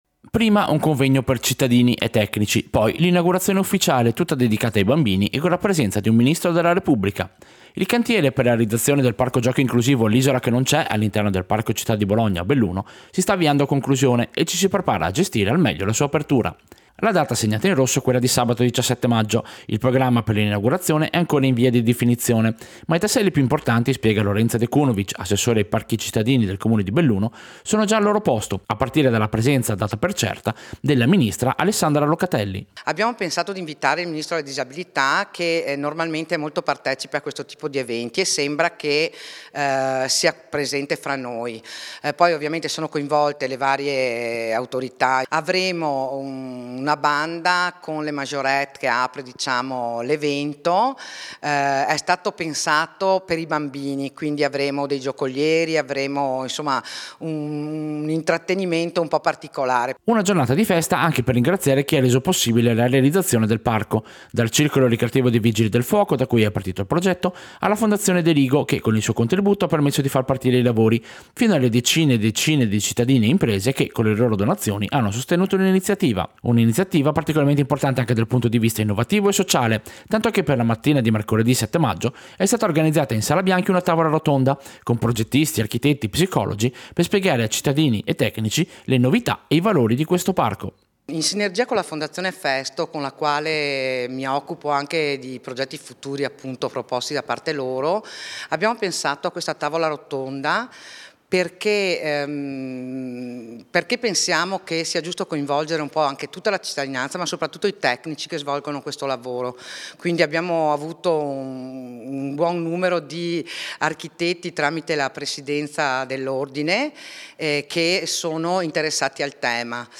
Servizio-Inaugurazione-e-convegno-Parco-Bologna-Isola-che-non-ce.mp3